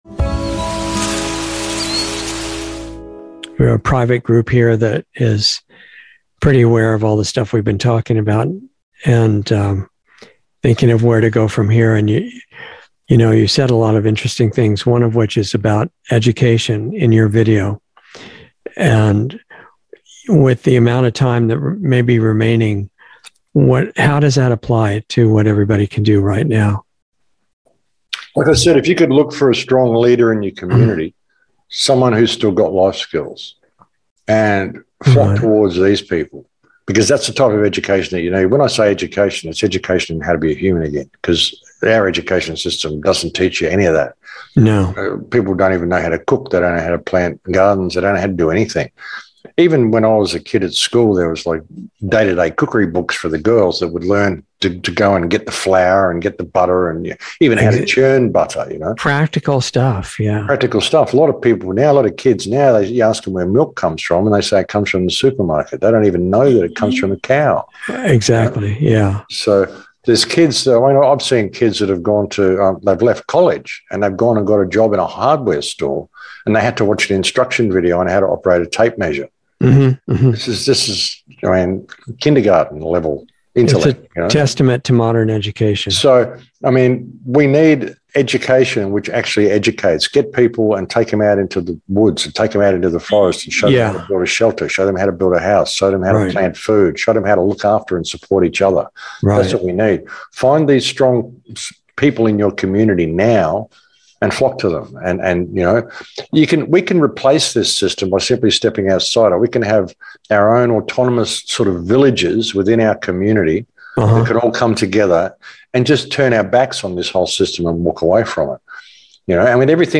Insider Interview